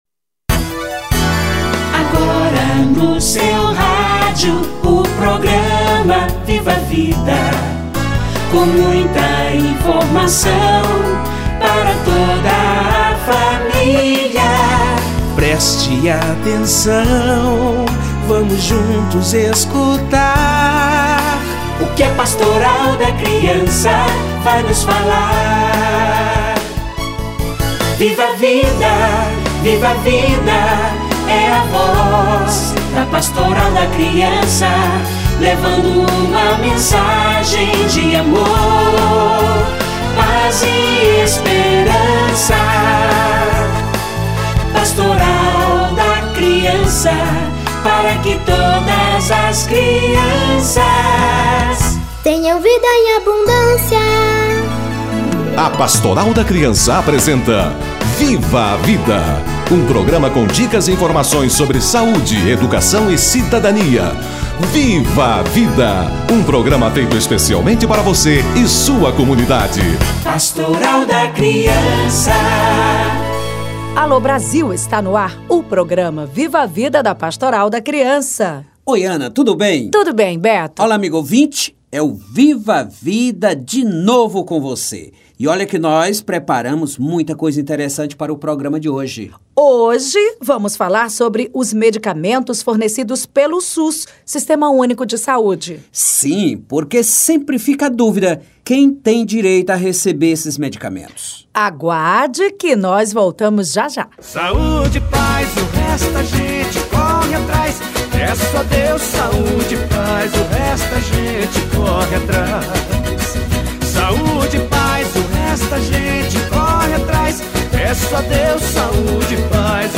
Medicamentos e suplementos: conheça seus direitos - Entrevista